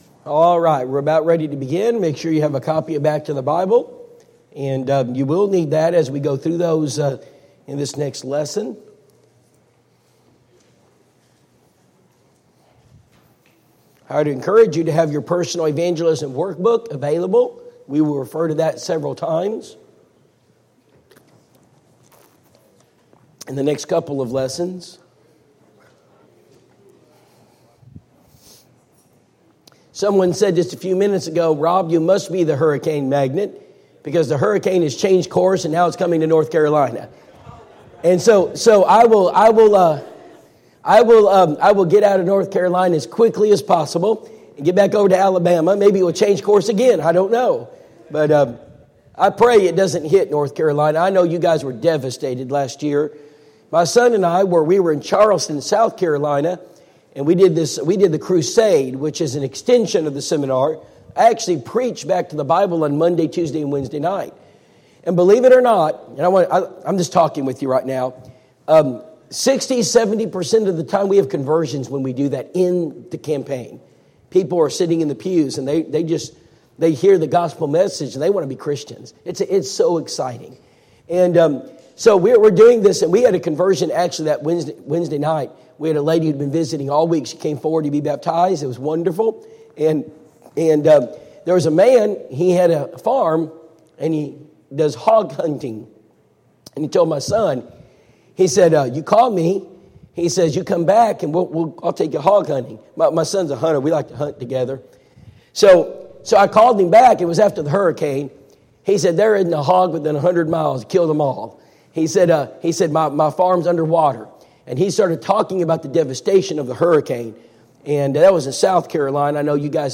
Personal Evangelism Seminar Service Type: Personal Evangelism Seminar Preacher